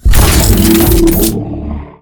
droidic sounds
taunt1.ogg